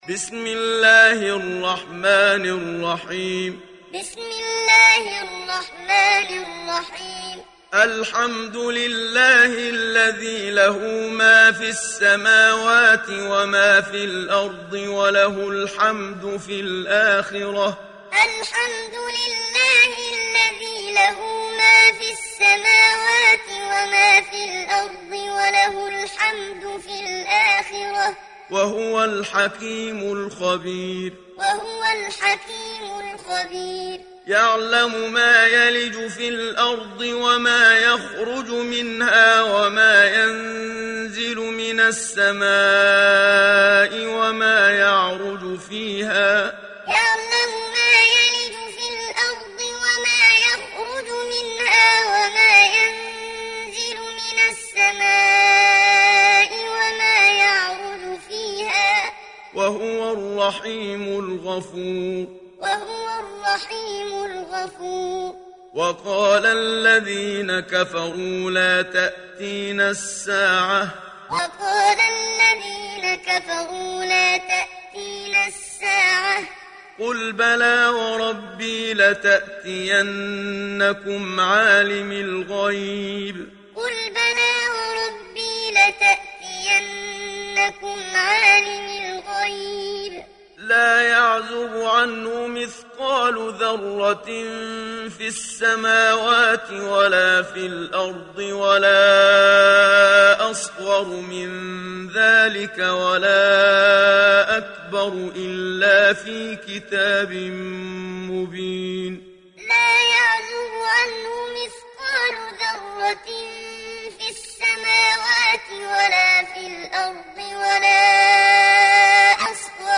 Télécharger Sourate Saba Muhammad Siddiq Minshawi Muallim